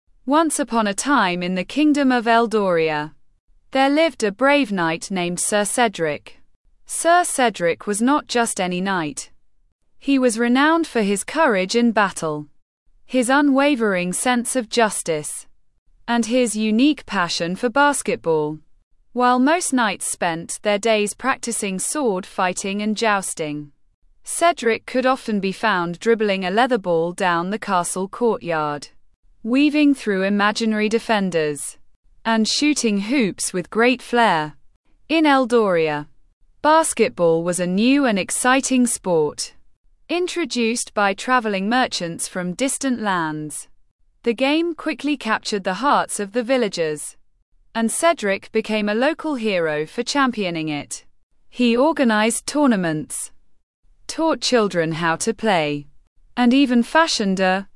Text-to-Speech
Generate speech from text
tts_example.mp3